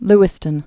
(lĭ-stən)